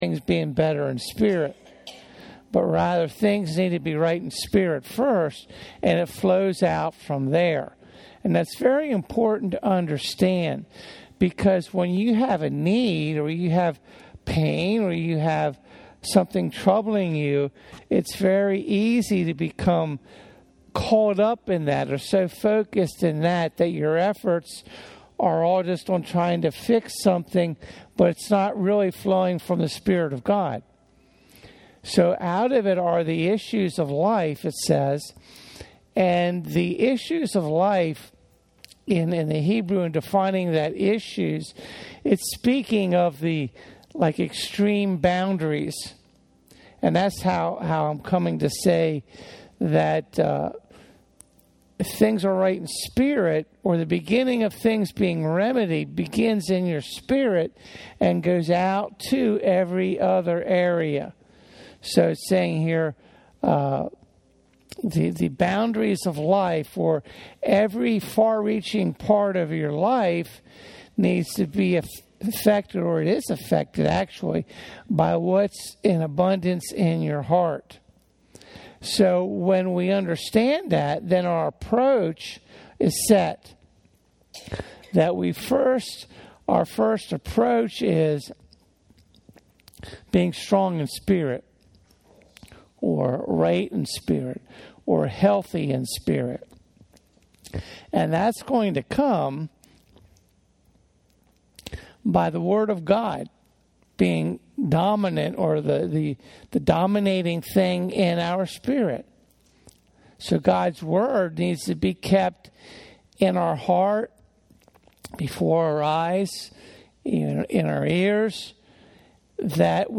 Sermons Archive - Page 25 of 60 - The Abundant Life Center